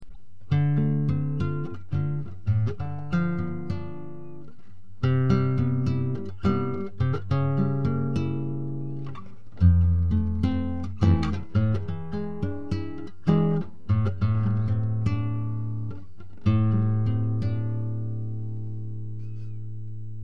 I have now finished repairing and tidying up this old classical. It has a nice tone and sounds much better with a saddle than it did without one.
The guitar looks okay and it plays really well with a nice sound.